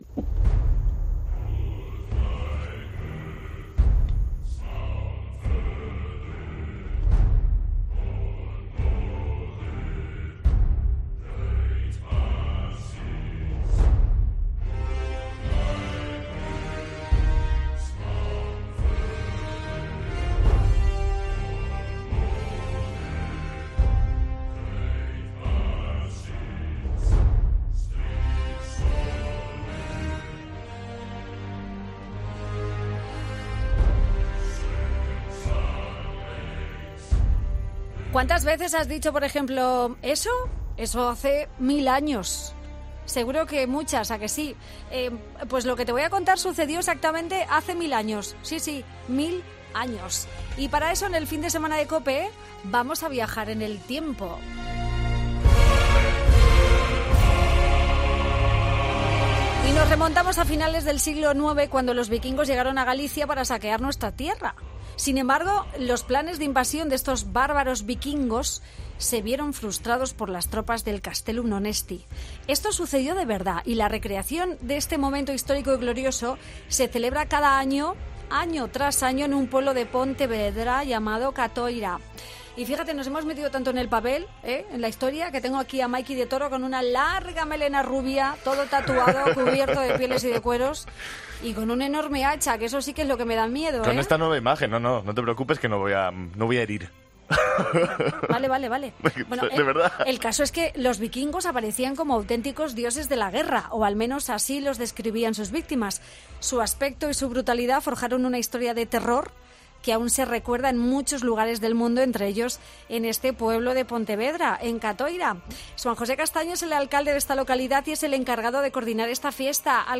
Conoce esta clásica y peculiar fiesta con el alcalde de Catoira y el presidente de una asociación que participa